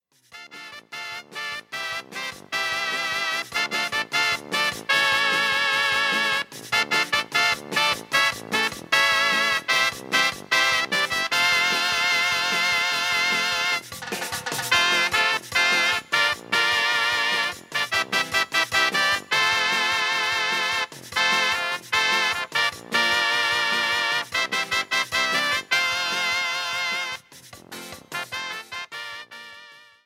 93-Banda-3.mp3